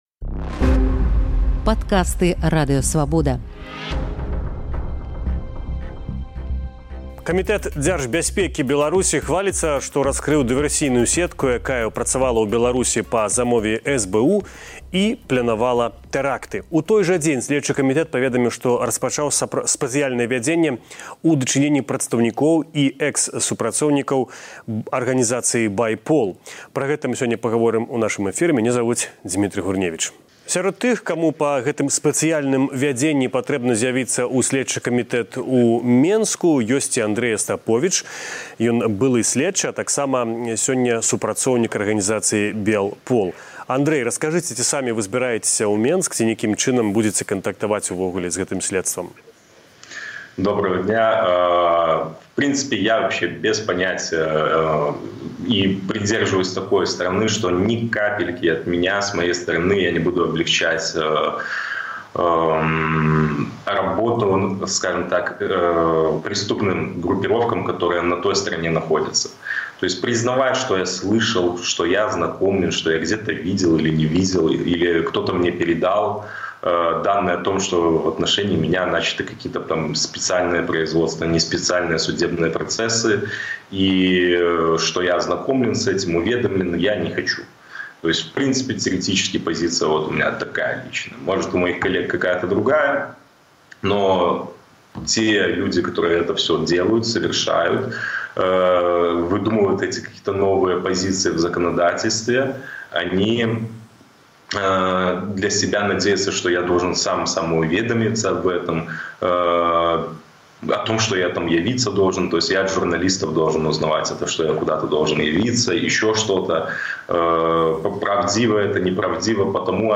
Як КДБ і ГУБАЗіК маніпулююць беларусамі. Расказвае былы сьледчы.